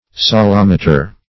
Search Result for " salometer" : The Collaborative International Dictionary of English v.0.48: salometer \sa*lom"e*ter\, n. See Salimeter .
salometer.mp3